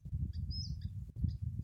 Guaracava-modesta (Sublegatus modestus)
Nome em Inglês: Southern Scrub Flycatcher
Localidade ou área protegida: Ruinas de los Quilmes
Condição: Selvagem
Certeza: Gravado Vocal